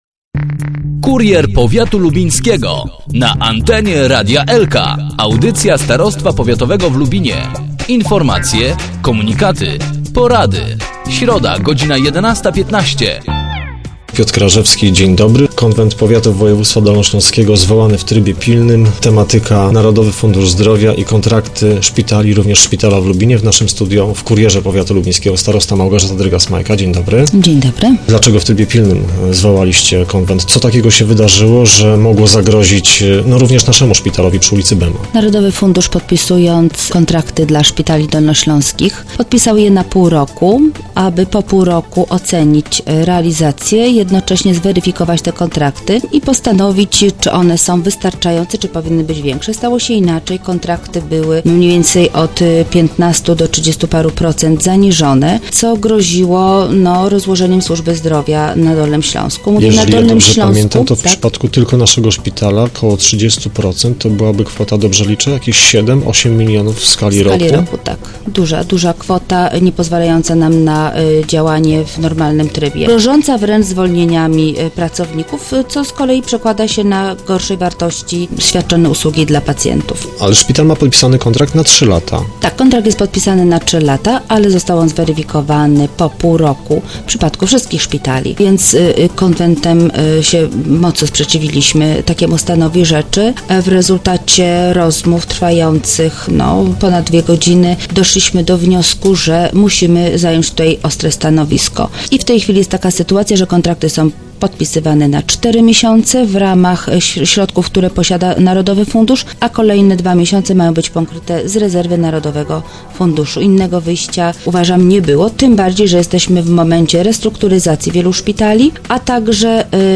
O zagrożeniu, które z tego wynikało w Kurierze Powiatu, mówiła starosta Małgorzata Drygas-Majka.